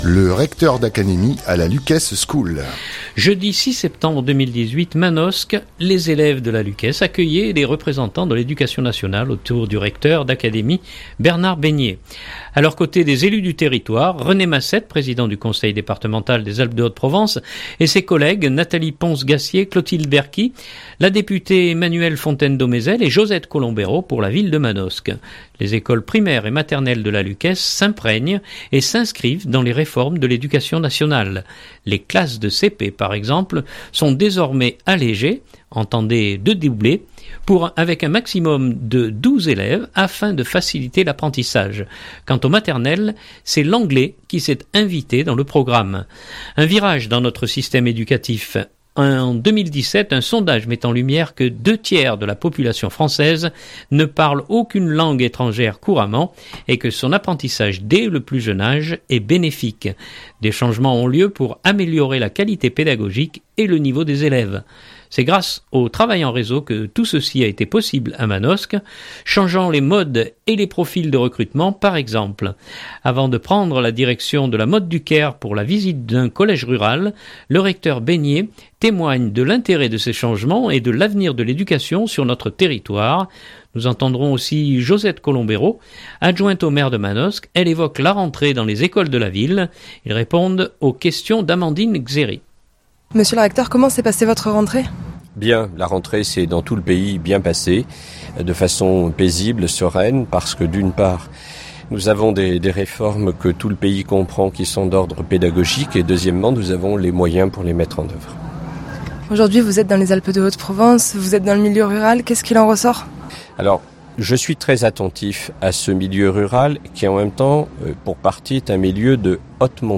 Avant de prendre la direction de La Motte du Caire pour la visite d’un collège rural, le Recteur Beignier témoigne de l’intérêt de ces changements et de l’avenir de l’éducation sur notre territoire. Nous entendrons aussi Josette Colombéro adjointe au maire de Manosque ; elle évoque la rentrée dans les écoles de la ville.